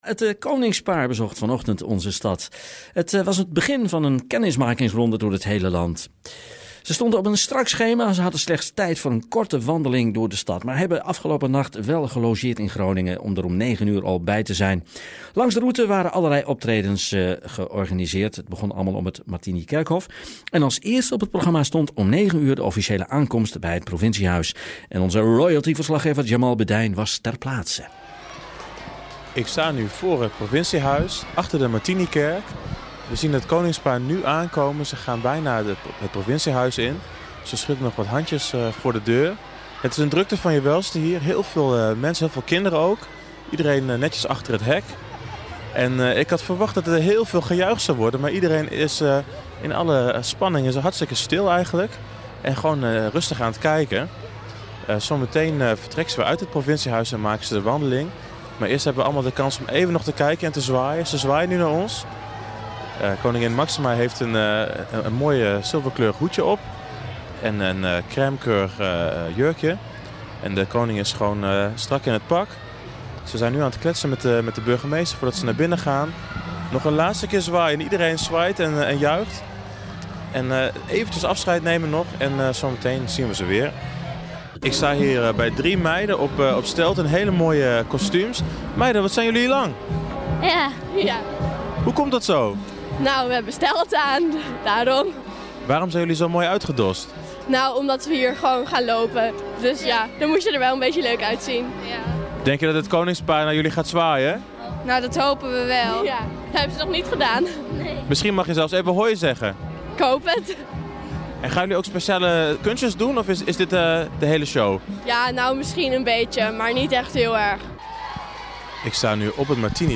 Op het Martinikerkhof kregen ze een optreden te zien van Jeugdcircus Santelli.